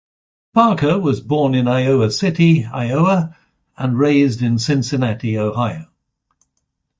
Ausgesprochen als (IPA)
/ˈpɑɹkɚ/